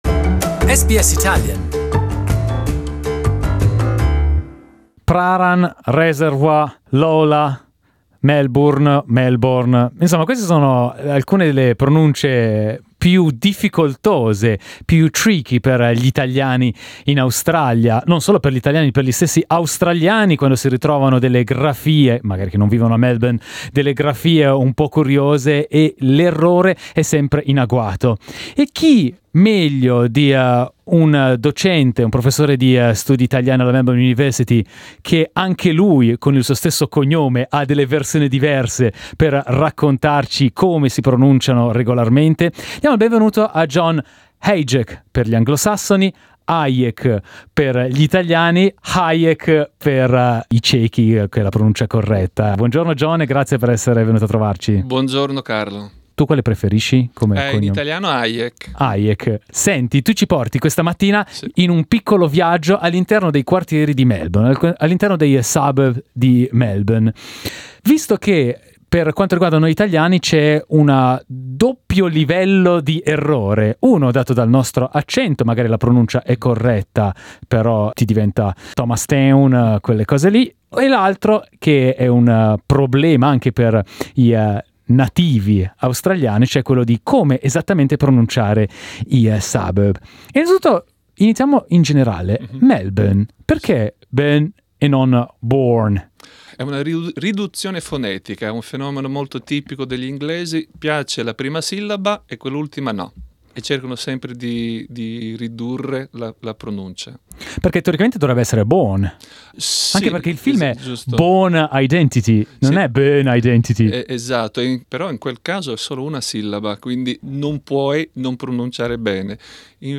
MÈL – bun è la pronuncia corretta, a meno che siate americani per cui diventerà Mel – BORNE.
(questa è difficile, ascoltate l’audio dell’intervista sotto il titolo ).